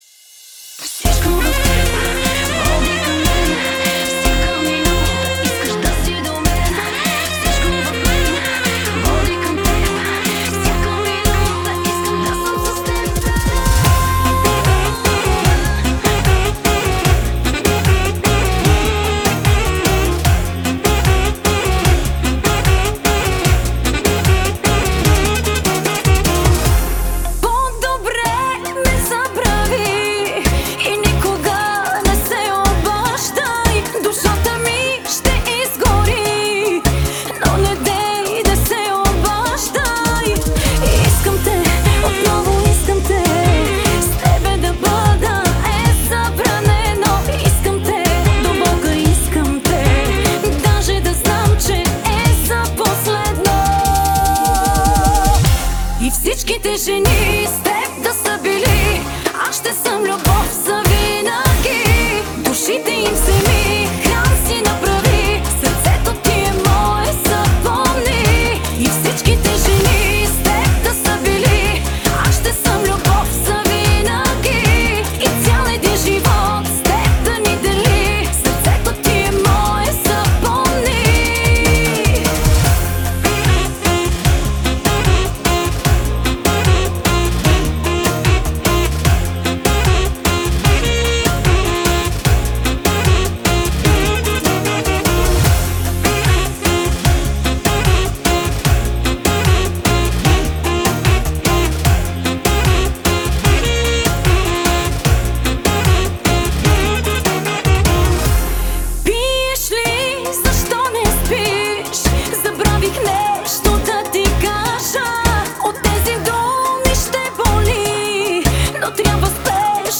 Поп-Фолк